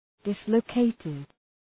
Shkrimi fonetik{‘dısləʋ,keıtıd}
dislocated.mp3